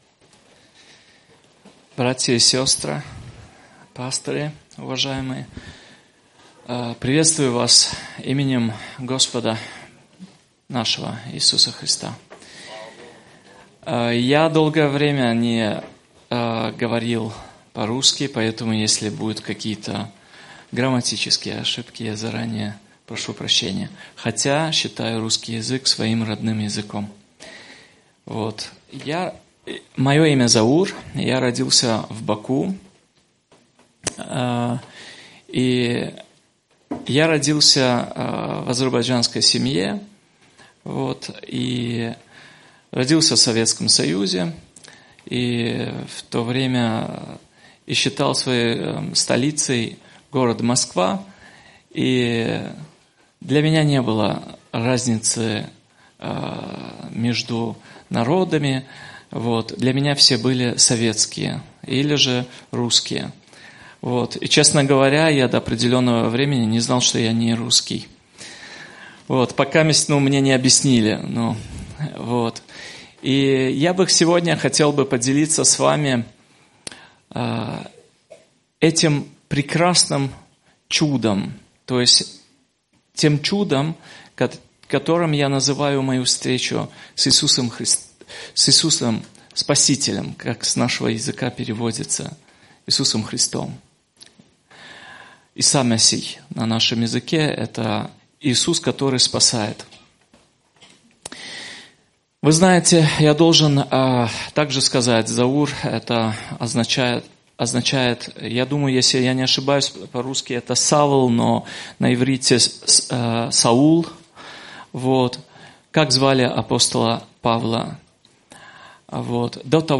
Брат свидетельствовал о том, как Бог привел его ко спасению.